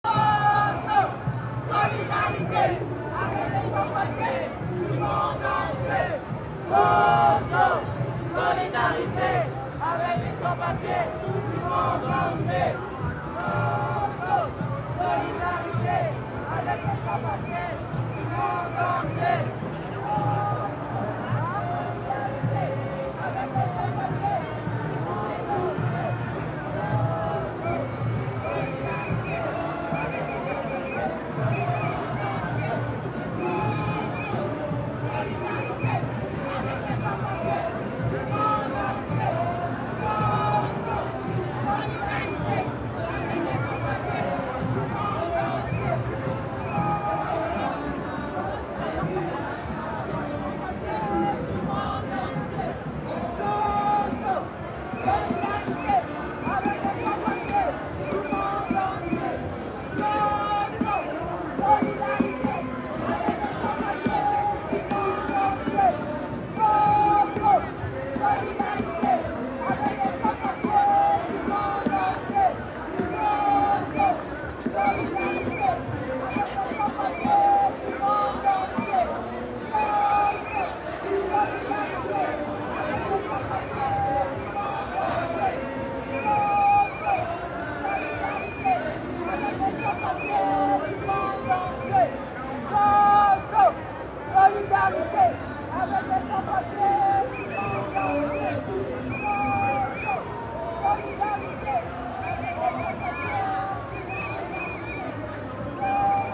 Demonstration in Paris.